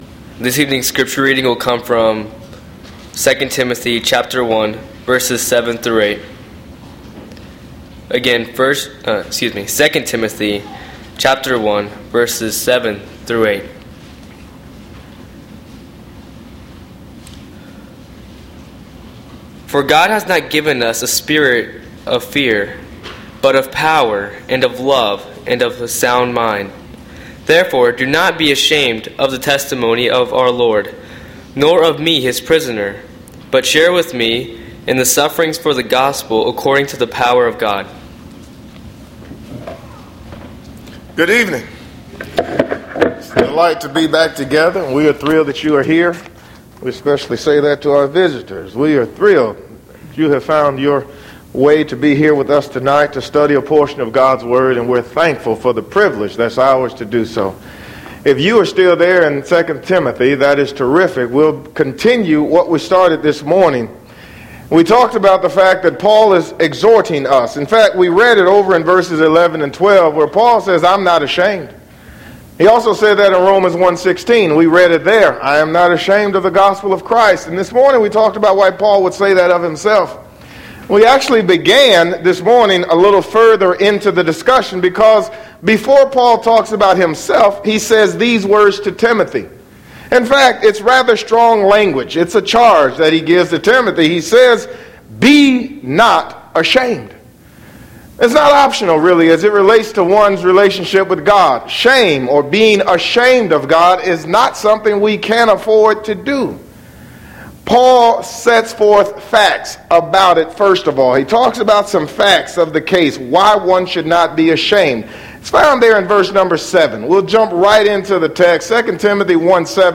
AM Worship